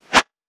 weapon_bullet_flyby_01.wav